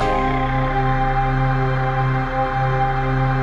ATMOPAD28 -LR.wav